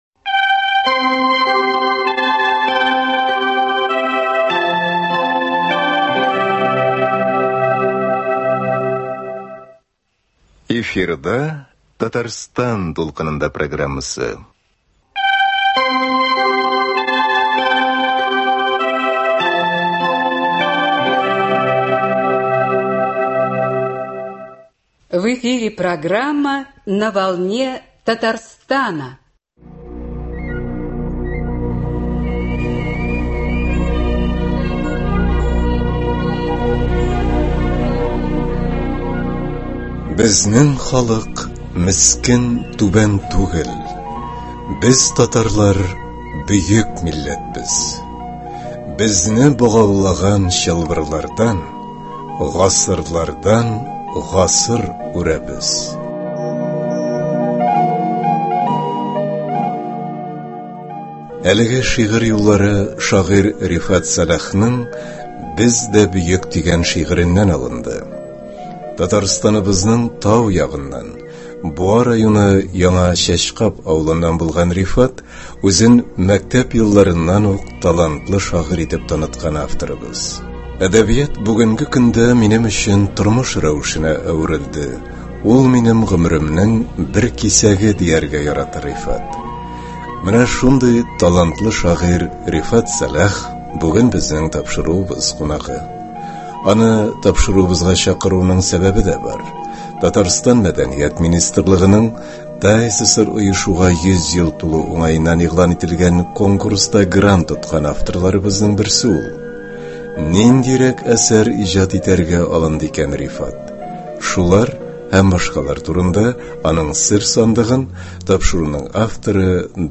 Әңгәмә барышында аның үзе укуында шигырьләрен дә ишетербез.